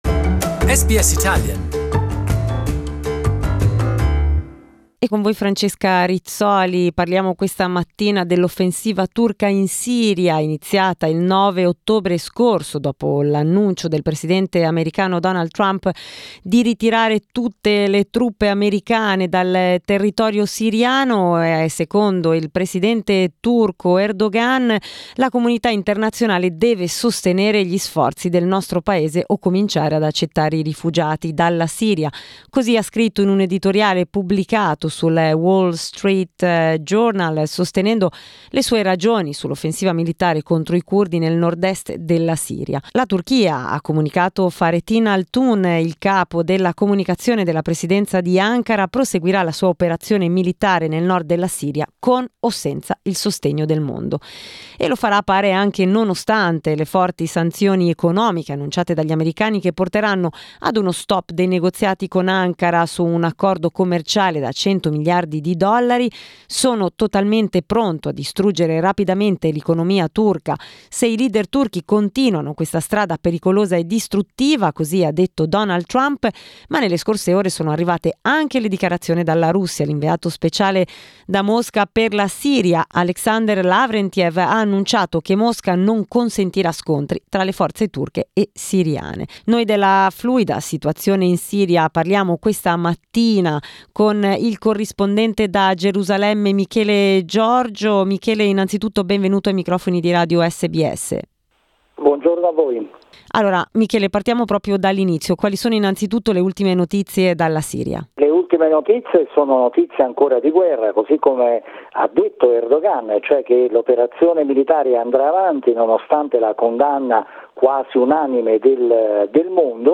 We talked about Turkey's military operations in Syria and the impact of it in the Middle East with correspondent from Jerusalem